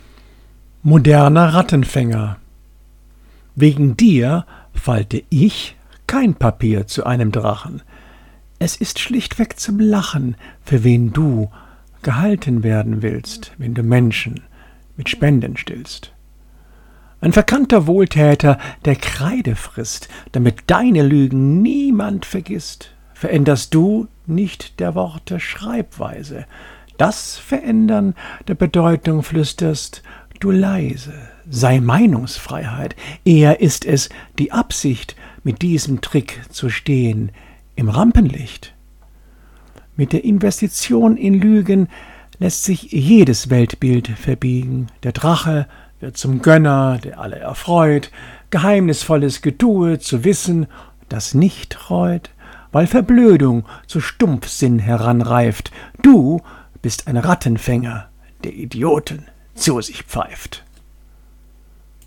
Gedichte – rezitiert
Insofern möchte ich hierbei so manche Gedichte für euch rezitieren.